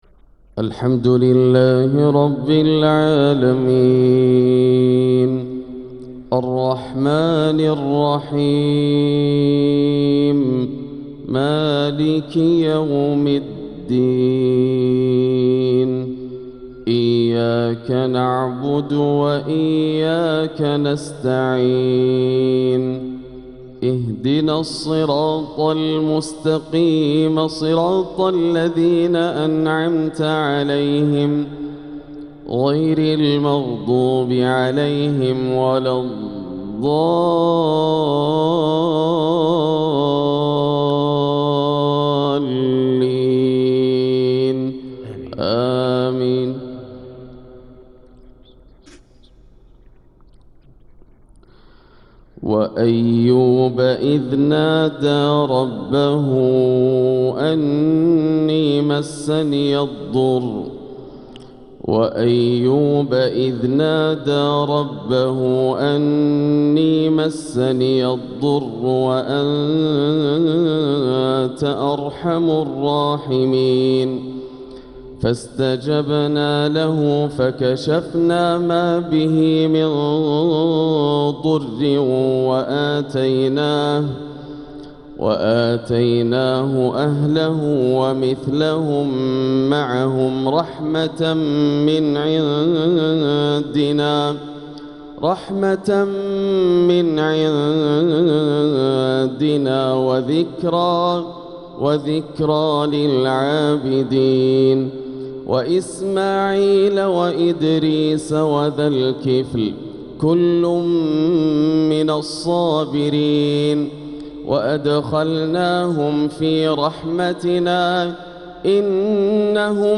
فجر الخميس 7-8-1446هـ | من سورة الأنبياء 83-107 | Fajr prayer from Surat al-Anbiya 6-2-2025 > 1446 🕋 > الفروض - تلاوات الحرمين